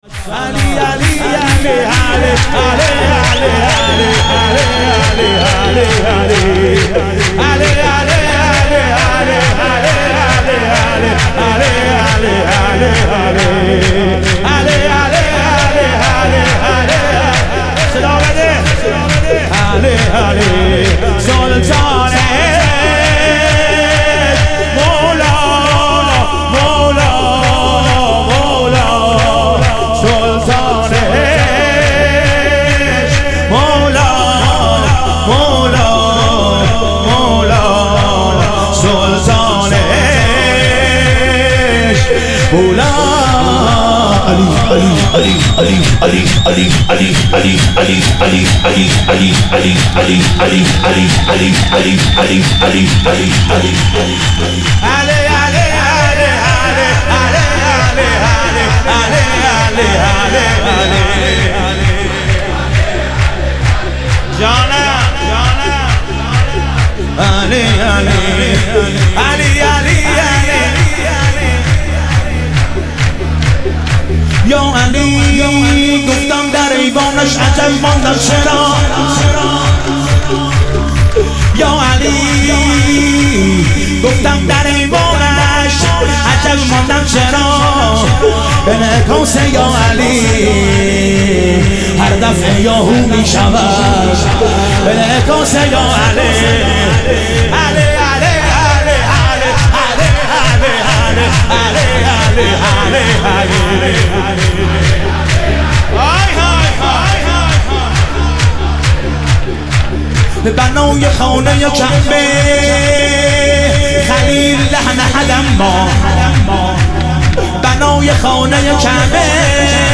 ظهور وجود مقدس امام رضا علیه السلام - شور